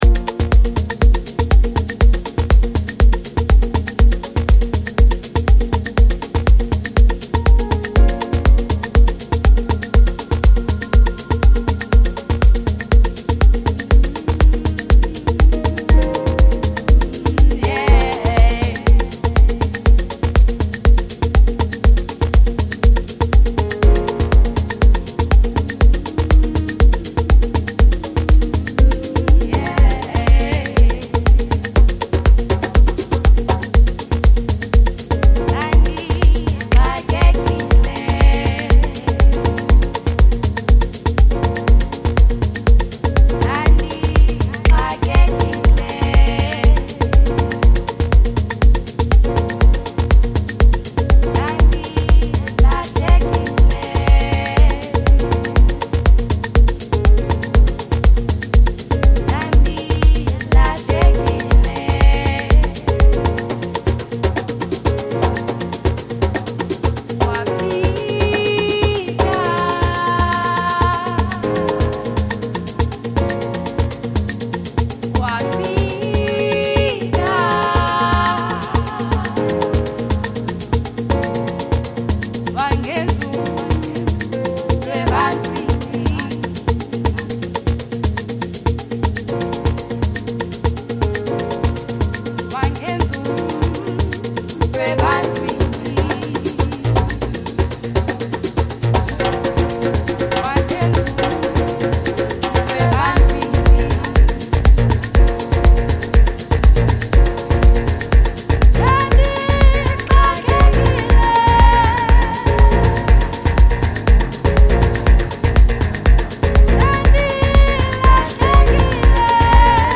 With its soulful melodies and captivating lyrics
With her soulful voice and incredible range